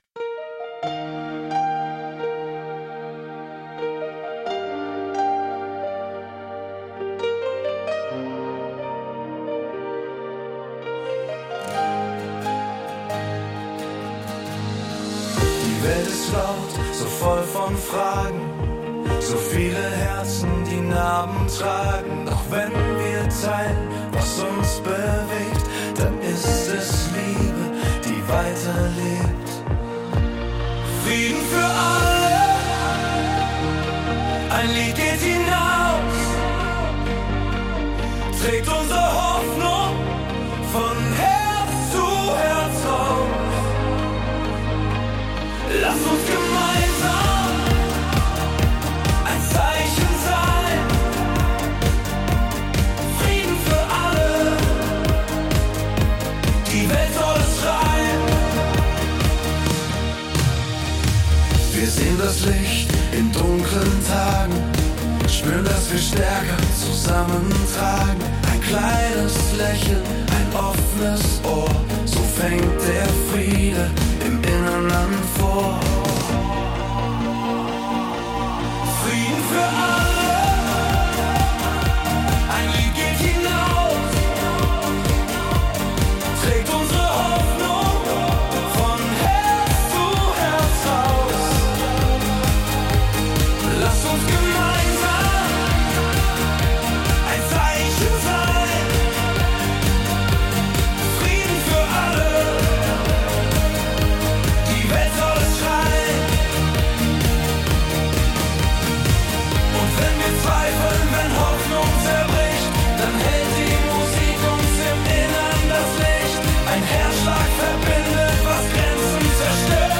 Pop, Soul